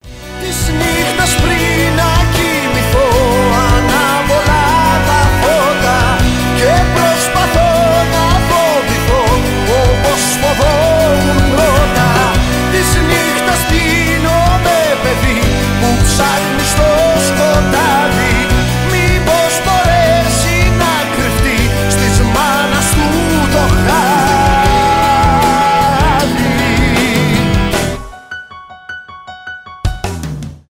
мелодичные , греческие , рок